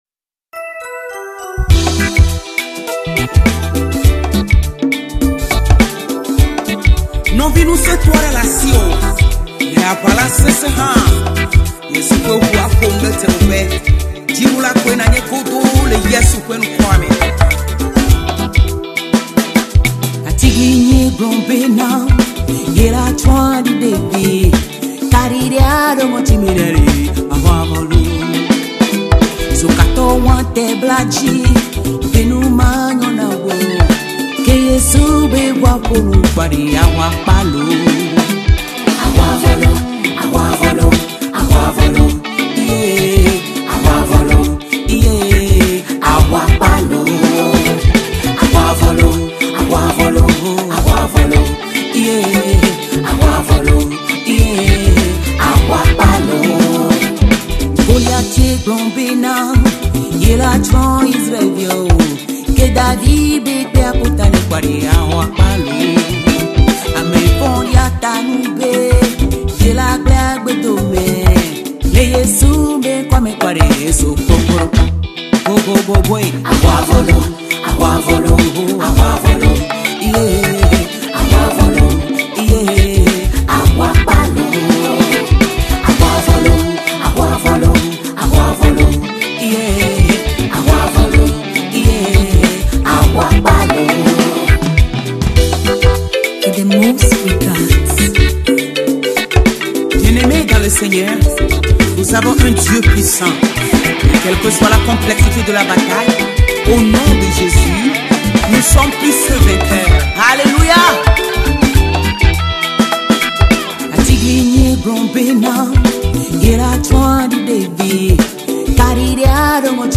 la musique gospel dans la diaspora africaine
Ma préférence pour le moment c'est le funk mélangé au reggae des fois avec peu de traditionel